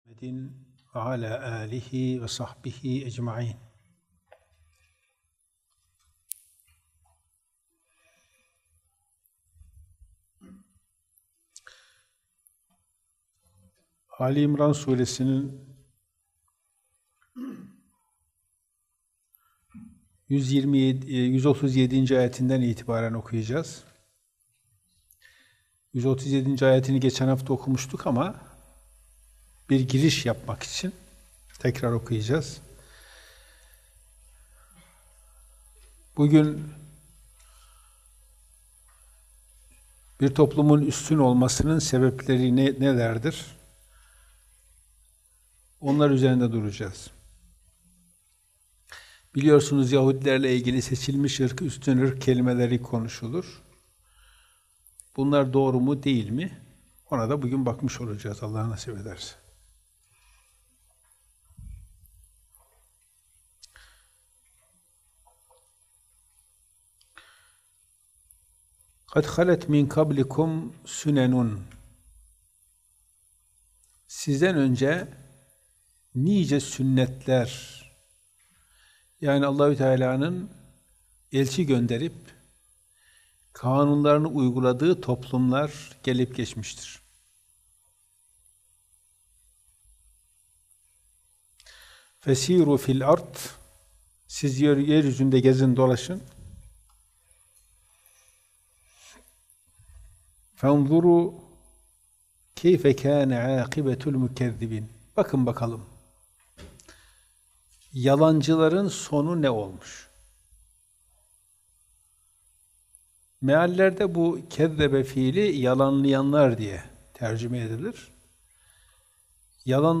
Ayetler – Allah’a Güvenenler En Üstünlerdir – Kuran Dersi